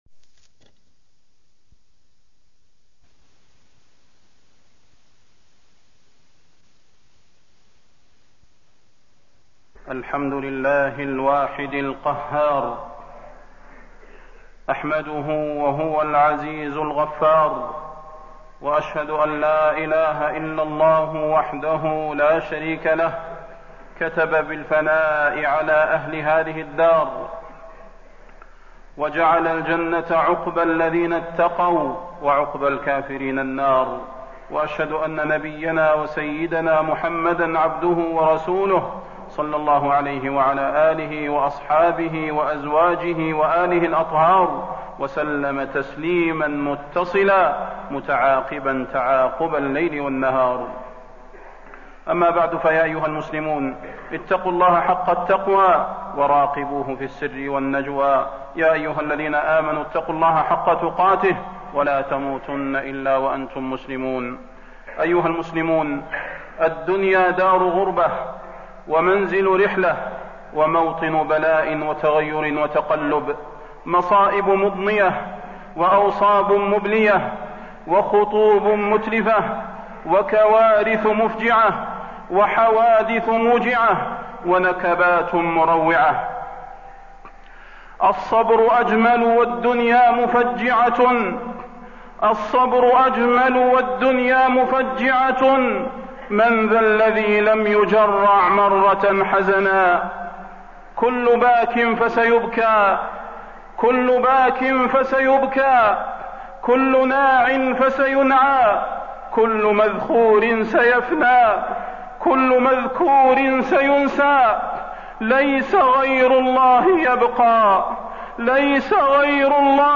تاريخ النشر ٢٤ ذو الحجة ١٤٣٠ هـ المكان: المسجد النبوي الشيخ: فضيلة الشيخ د. صلاح بن محمد البدير فضيلة الشيخ د. صلاح بن محمد البدير عظم الجزاء مع عظم البلاء